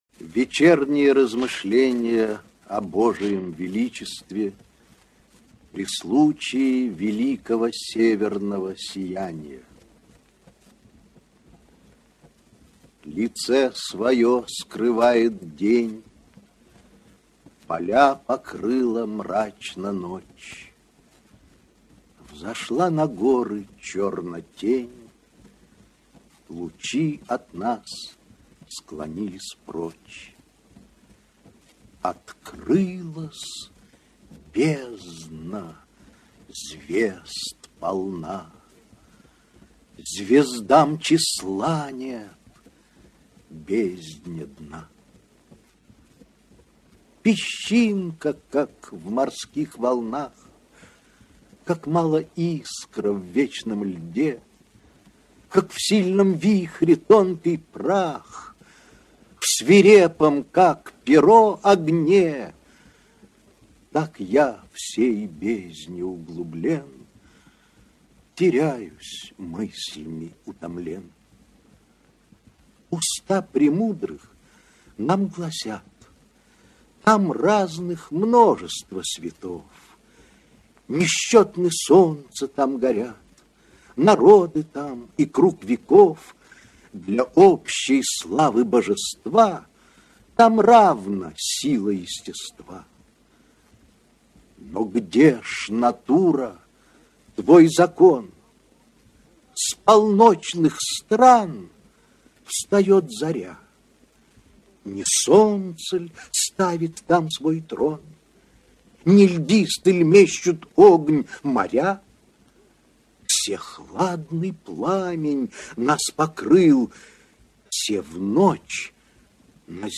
Прослушивание аудиозаписи оды «Вечернее размышление...» с сайта «Старое радио». Исп. Я. Смоленский.